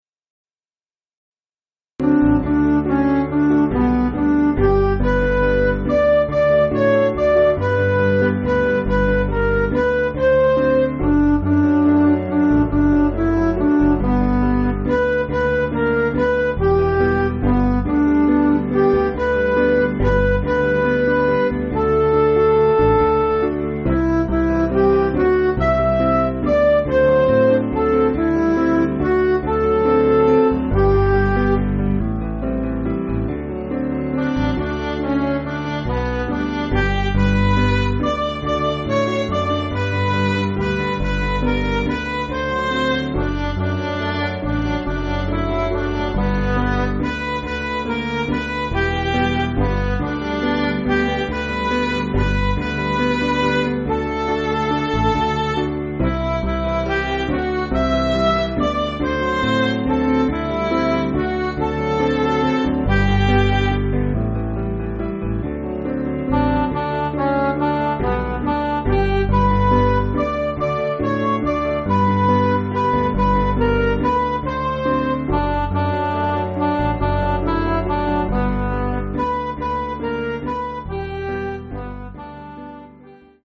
Piano & Instrumental
(CM)   8/G